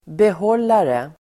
Uttal: [beh'ål:are]